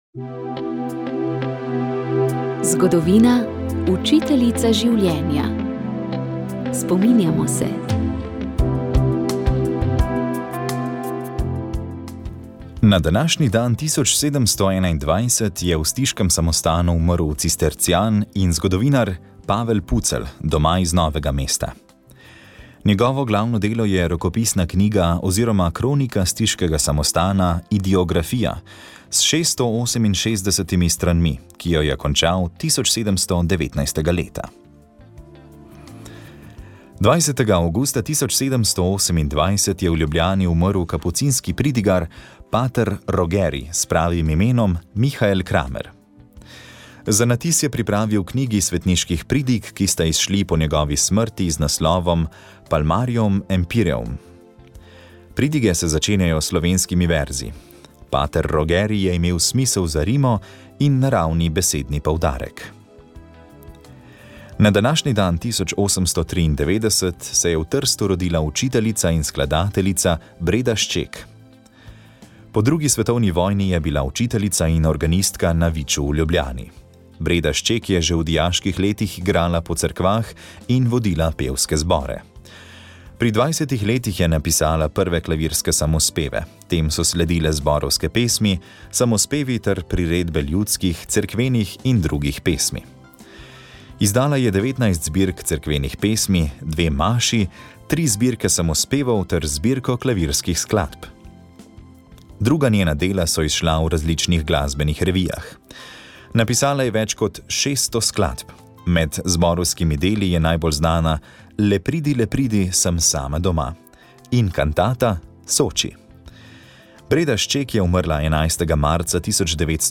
Še tri smo prebrali v tokratni oddaji.